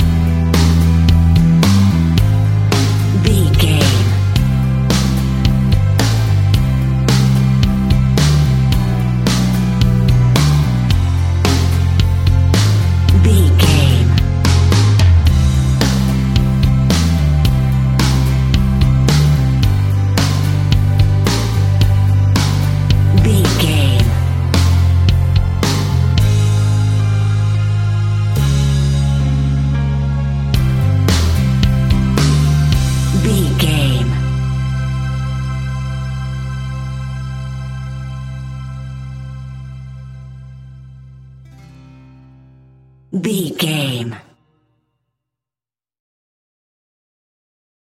Ionian/Major
calm
melancholic
happy
energetic
smooth
uplifting
electric guitar
bass guitar
drums
pop rock
indie pop
organ